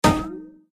wood_damage_01.ogg